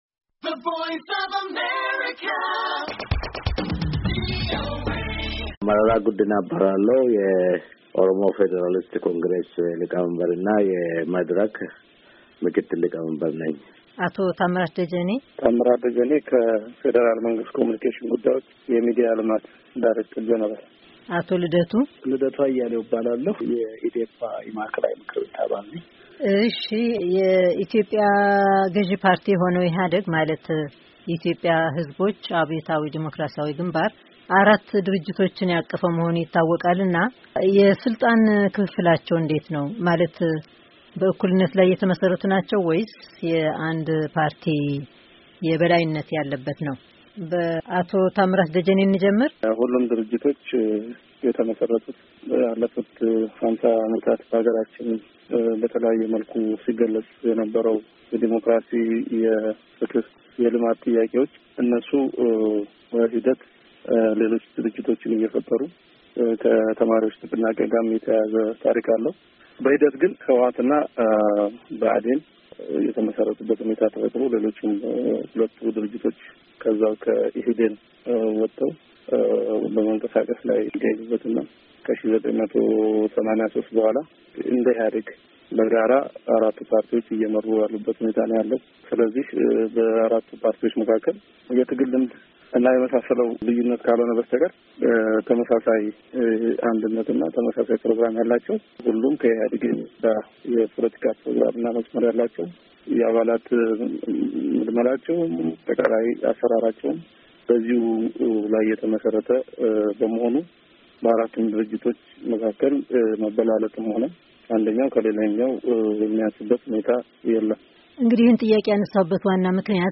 ከፖለቲካ ፓርቲ አባላትና አመራሮች ጋር የተደረገ ቃለ ምልልስ